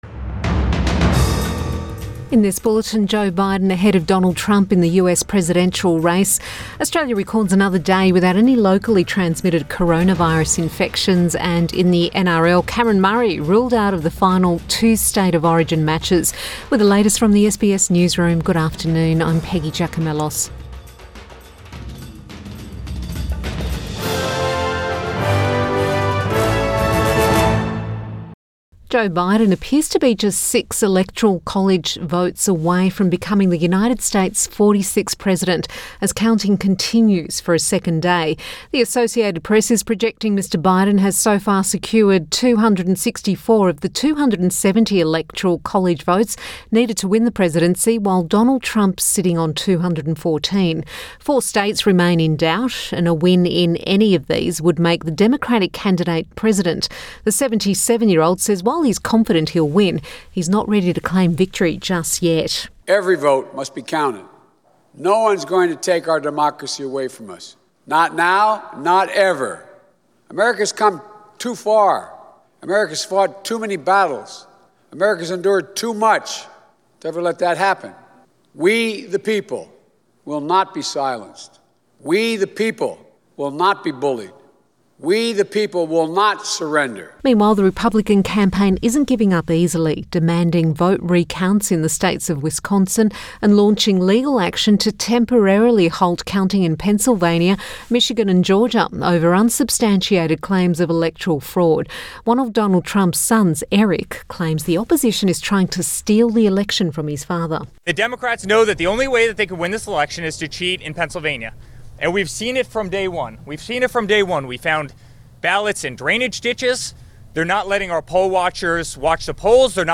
PM bulletin 5 November 2020